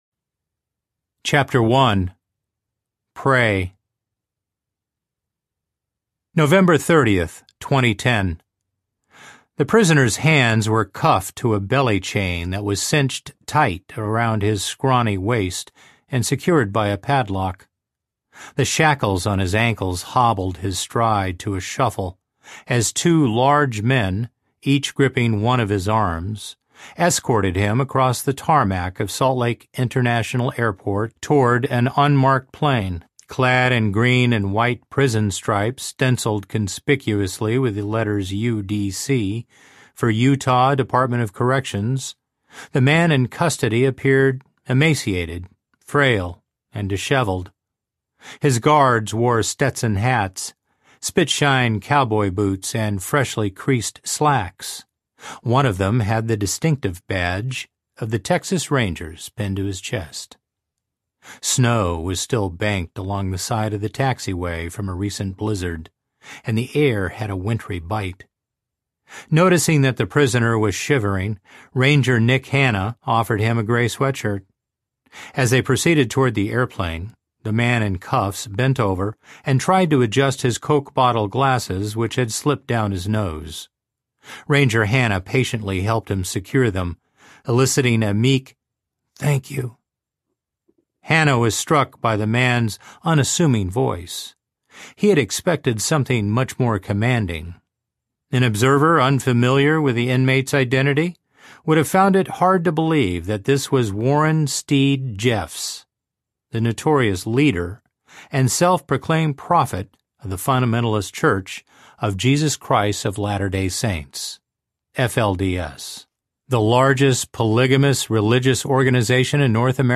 Prophet’s Prey Audiobook
12.7 Hrs. – Unabridged